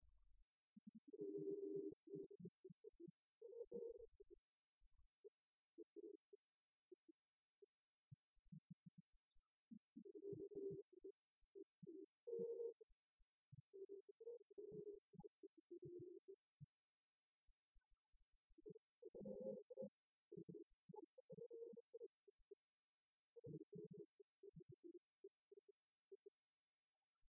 Genre strophique
Concert de la chorale des retraités
Pièce musicale inédite